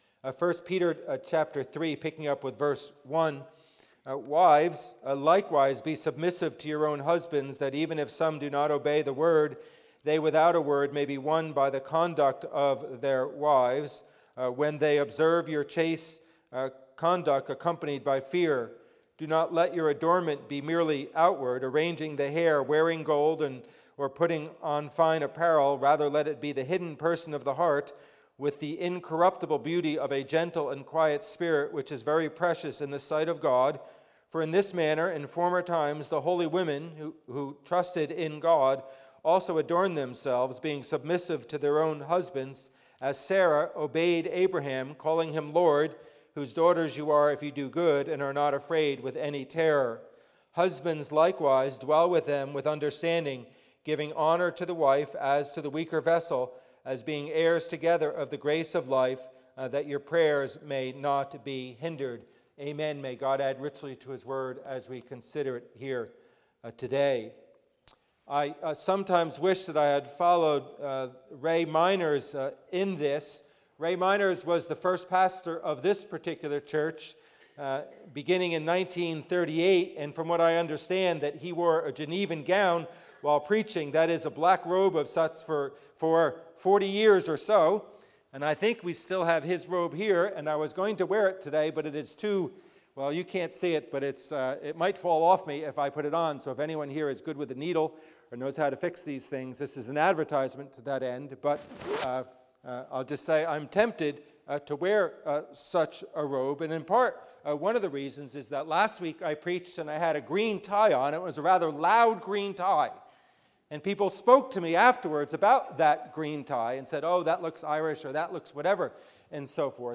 A Word to Husbands and Wives, Part Three–A Lesson on Lasting Feminine Beauty, In & Out Preacher: